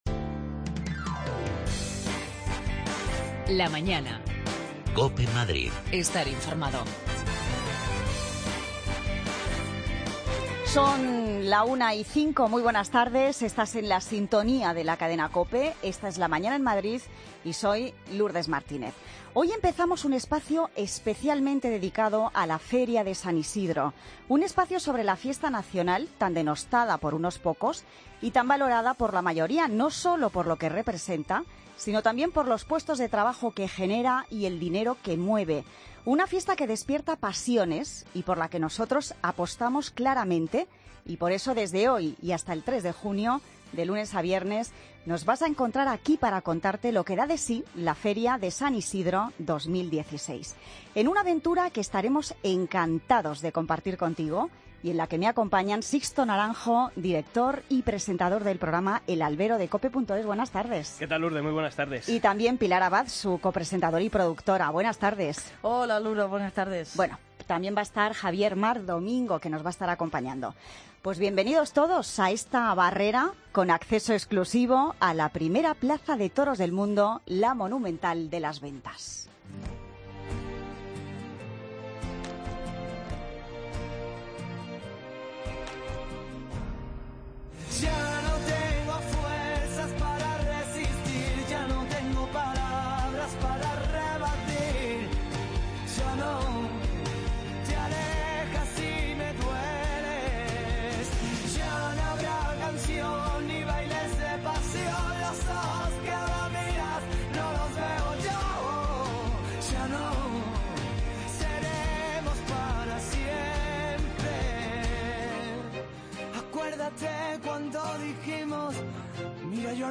Tertulia Taurina Feria San Isidro COPE Madrid, viernes 6 de mayo de 2016